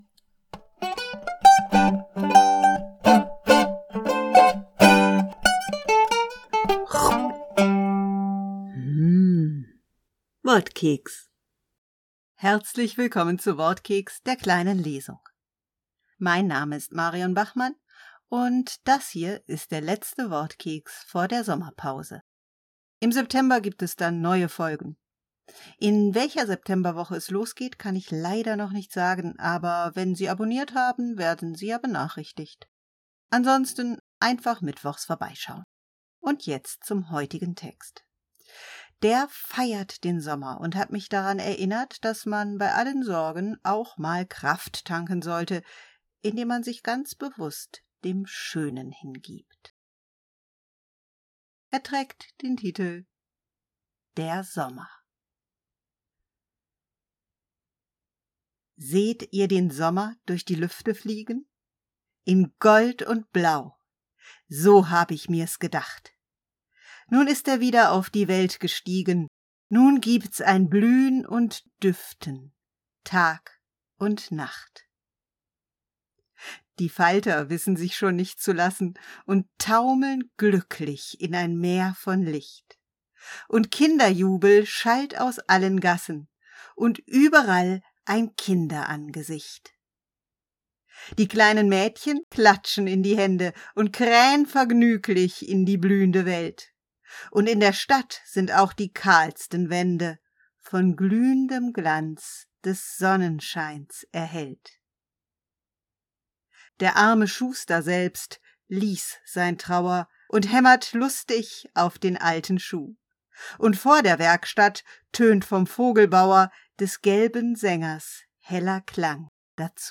Die heutige kleine Lesung nimmt uns hinaus, mitten in den Sommer.